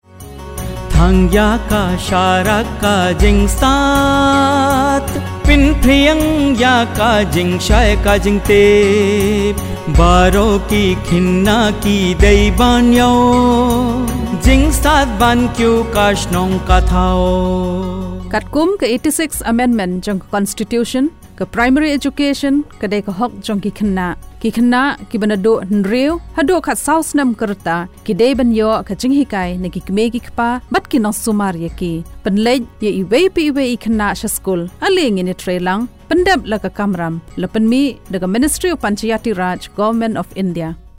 101 Fundamental Duty 11th Fundamental Duty Duty for all parents and guardians to send their children in the age group of 6-14 years to school Radio Jingle Khasi